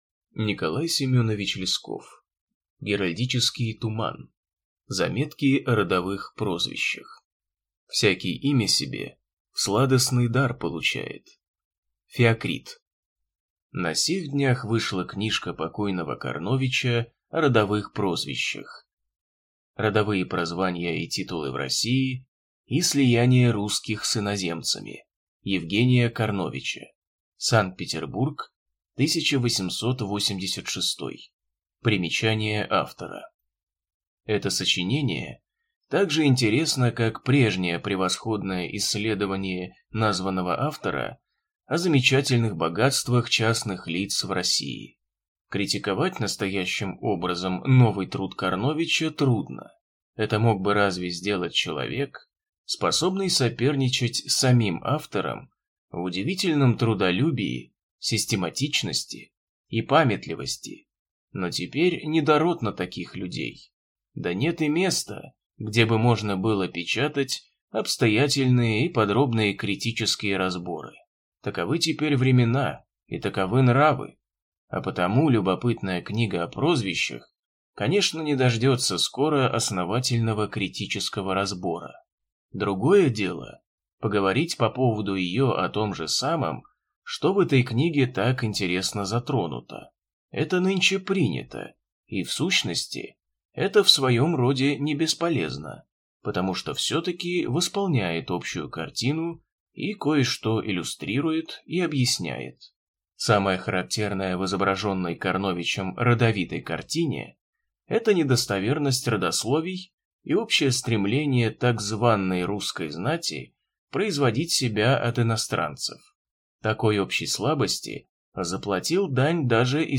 Аудиокнига Геральдический туман | Библиотека аудиокниг